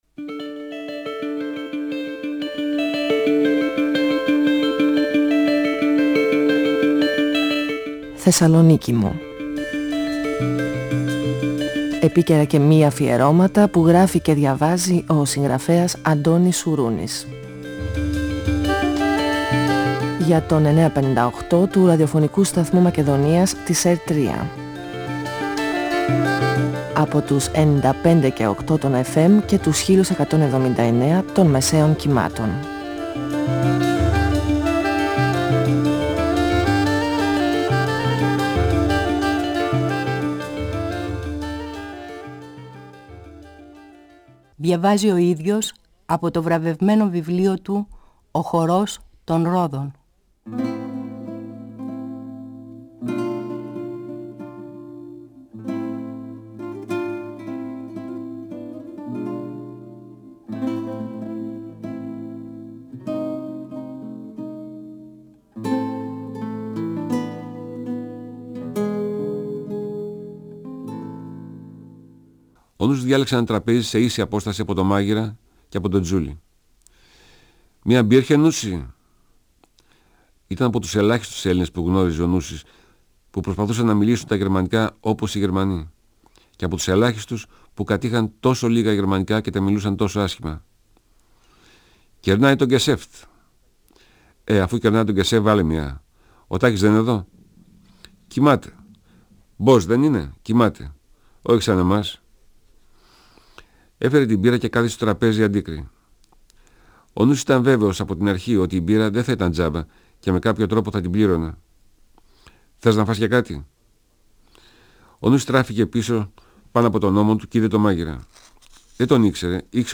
Ο συγγραφέας Αντώνης Σουρούνης (1942-2016) διαβάζει το πρώτο κεφάλαιο από το βιβλίο του «Ο χορός των ρόδων», εκδ. Καστανιώτη, 1994. Ο Νούσης βρίσκεται στην ταβέρνα του Τάκη.